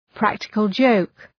practical-joke.mp3